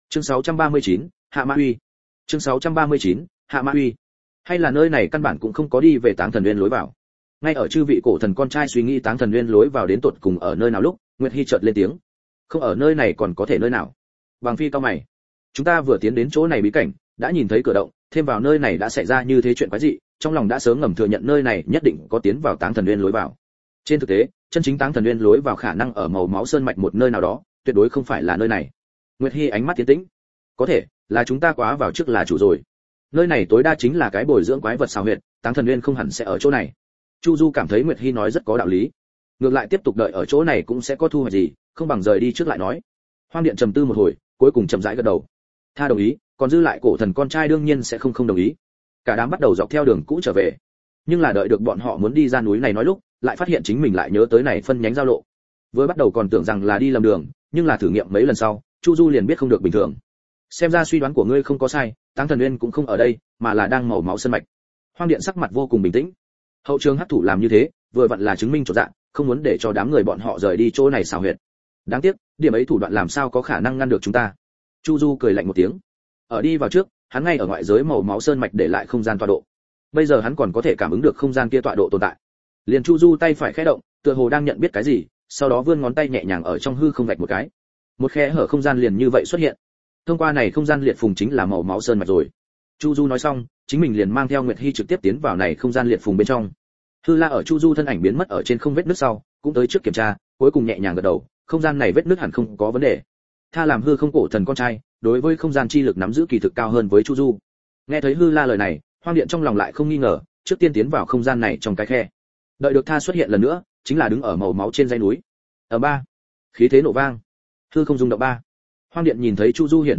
Người Đang Trừ Ma Ty, Võ Công Chính Mình Tiến Hóa Audio - Nghe đọc Truyện Audio Online Hay Trên TH AUDIO TRUYỆN FULL